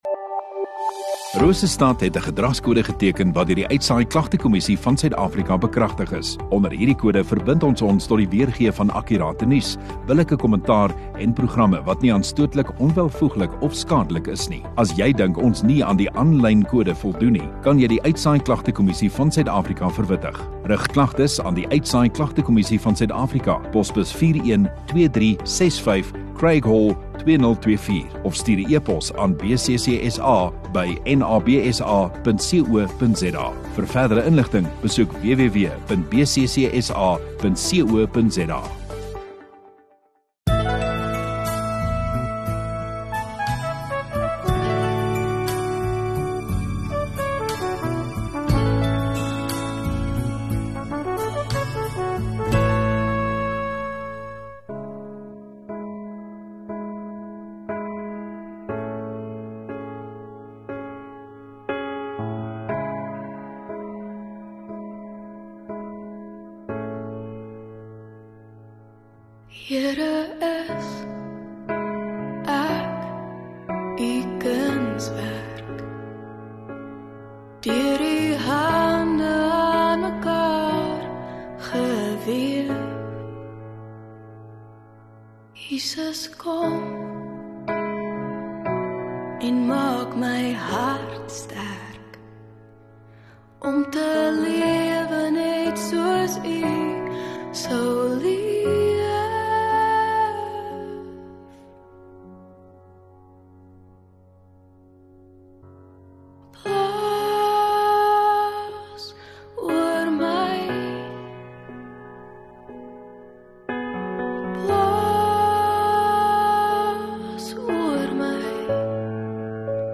25 Dec KERSFEES Woensdagaand Erediens